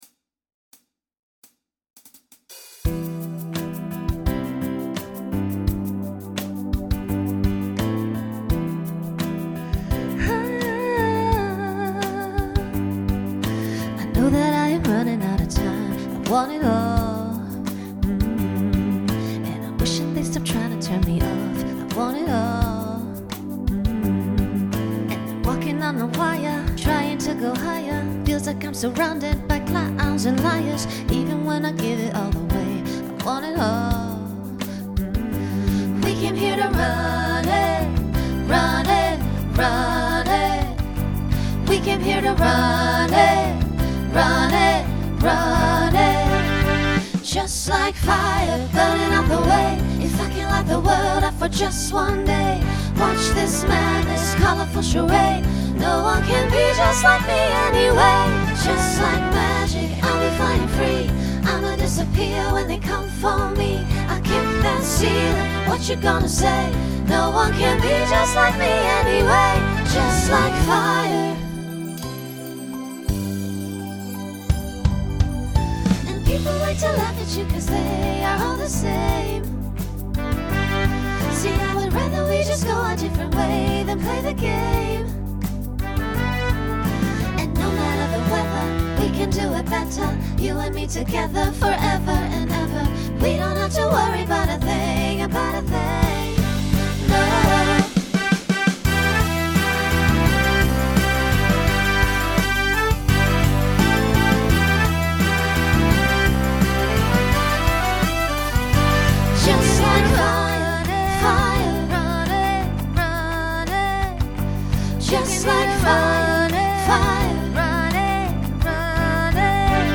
Genre Pop/Dance
Mid-tempo Voicing SSA